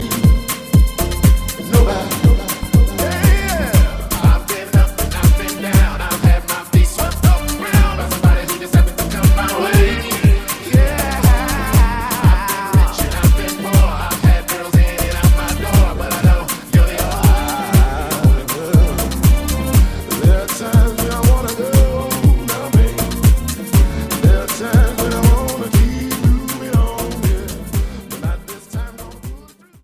REMIX TRACKS